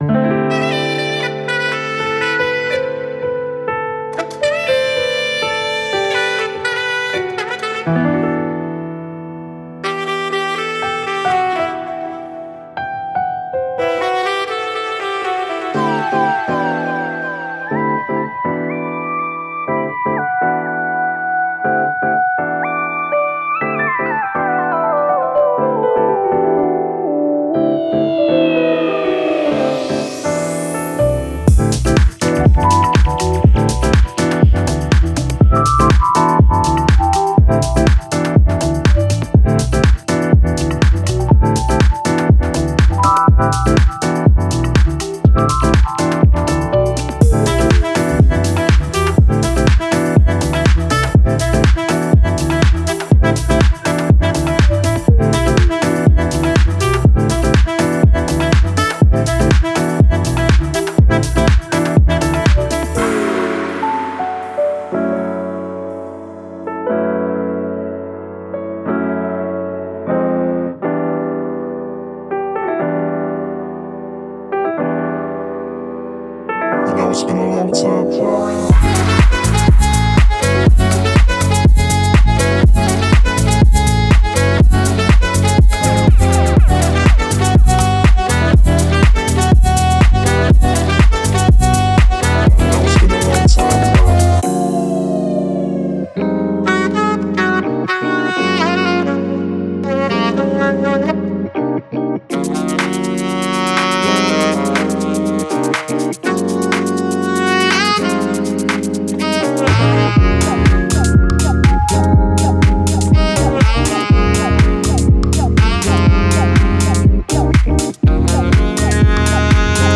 Genre:House
デモサウンドはコチラ↓
110, 115, 116, 117, 118, 120, 122, 126 BPM